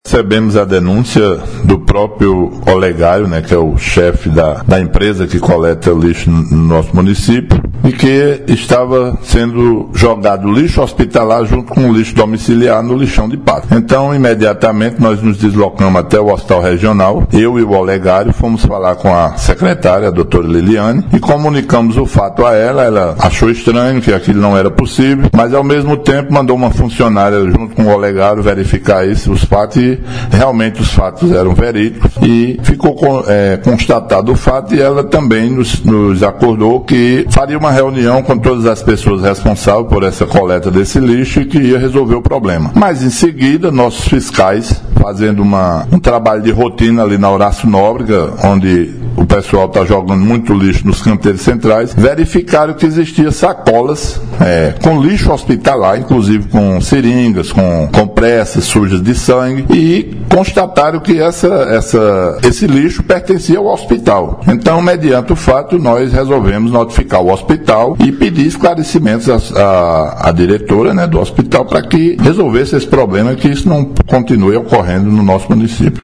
Fala do secretário municipal de Meio Ambiente, Natércio Alves –